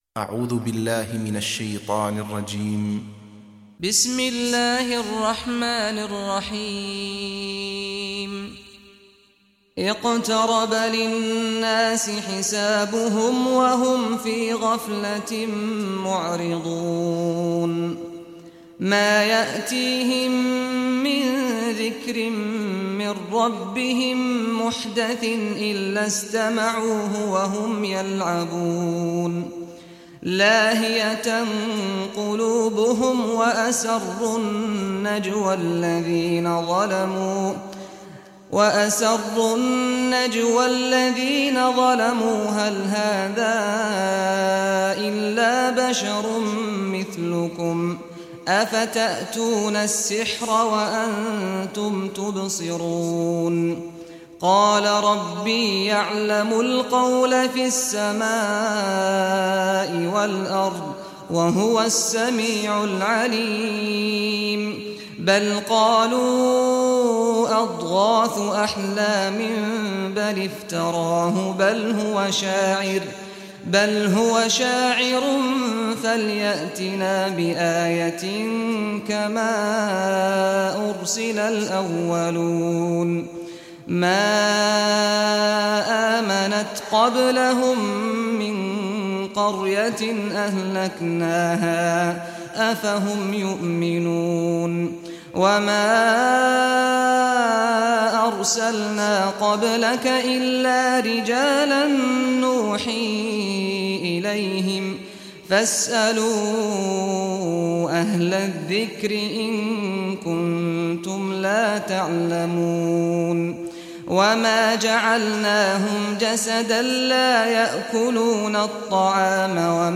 Surah Al-Anbya Recitation by Saad al Ghamdi
Surah Al-Anbya, listen or play online mp3 tilawat / recitation in Arabic in the beautiful voice of Imam Sheikh Saad al Ghamdi.
21-surah-anbiya.mp3